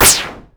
ihob/Assets/Extensions/RetroGamesSoundFX/Shoot/Shoot04.wav at master
Shoot04.wav